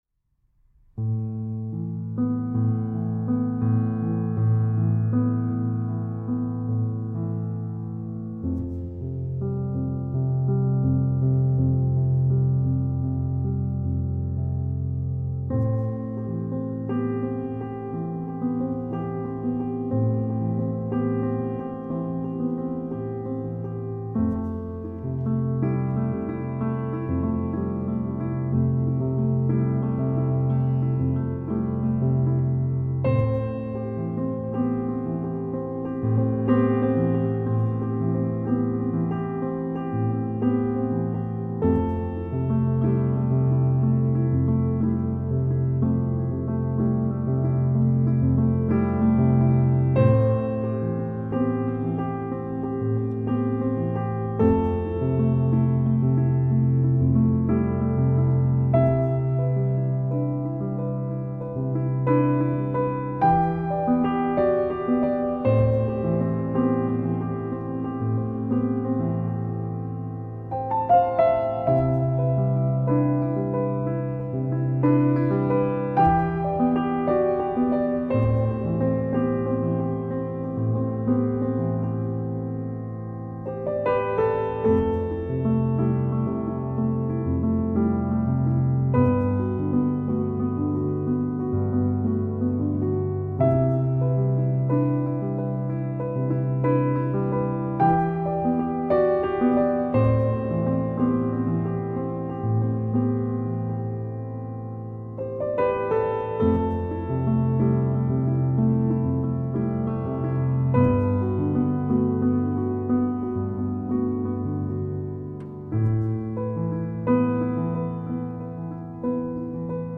آرامش بخش , پیانو , مدرن کلاسیک , موسیقی بی کلام
پیانو آرامبخش